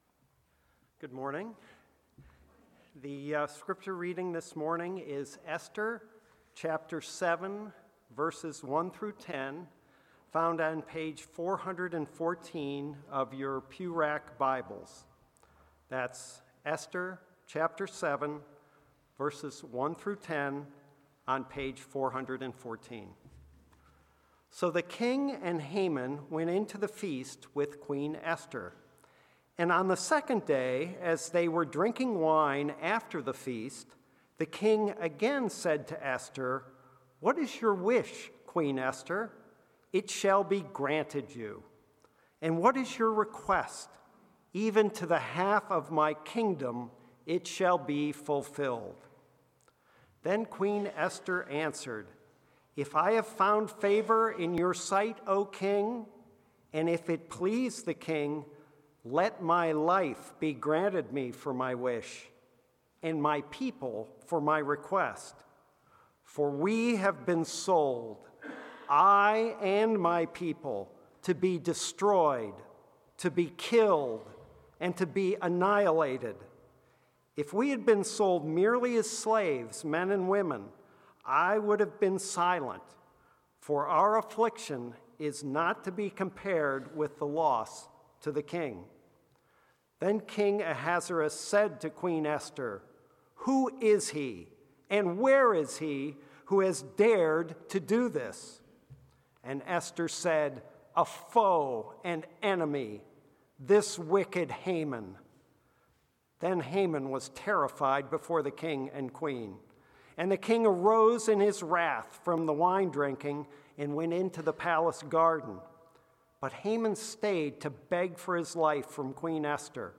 Passage: Esther 7:1-10 Sermon